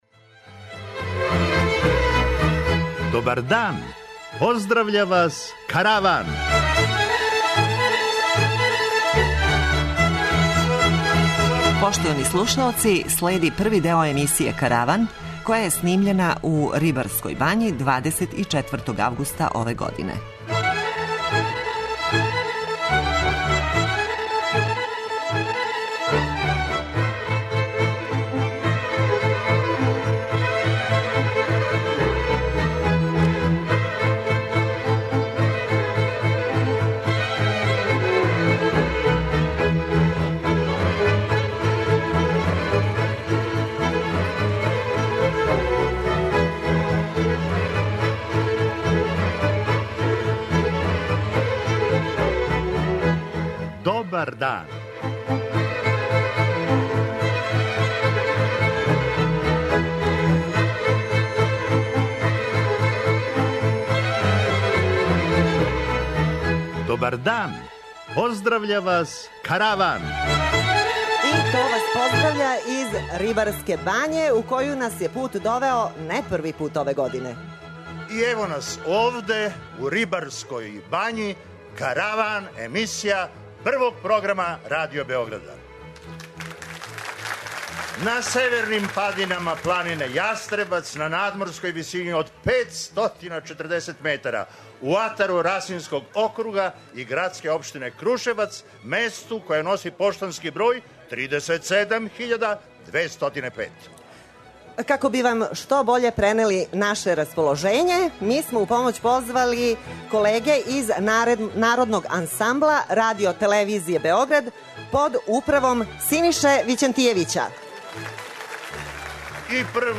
Још једно специјално издање. Овога пута житељи Рибарске бање имали су прилику да буду део јединственог и непоновљивог догађаја - присуствовали су јавном снимању Каравана, а ви сте у прилици да га чујете.